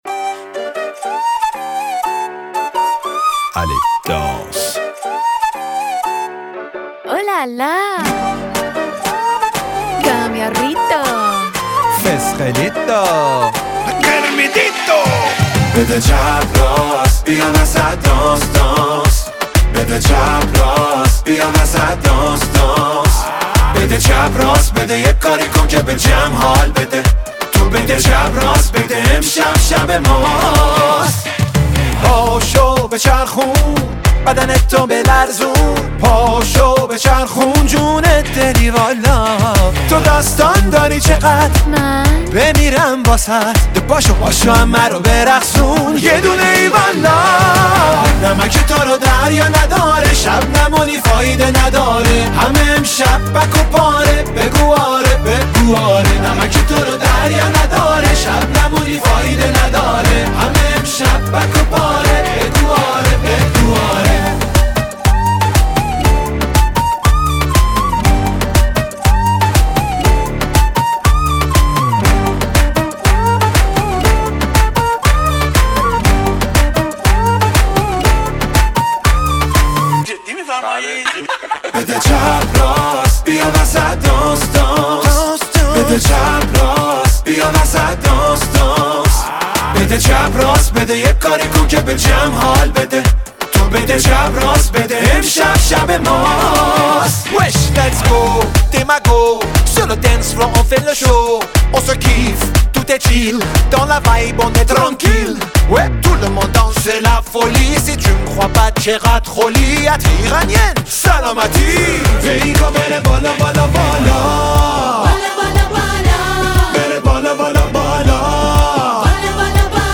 شاد و پر انرژی